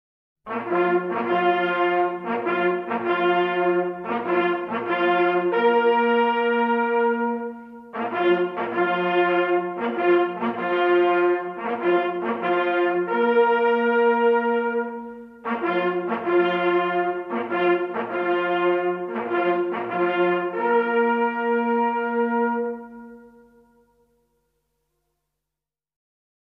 Die Jagdhornsignale sind die lauten Mitteilungen in der Jagd. Sie dienen einerseits den Ablauf einer Gruppenjagd zu koordinieren oder den erlegten Tieren mit den Jagdhörnern die letzte Ehre zu erweisen. Nachfolgend ein paar Jagdhornstücke.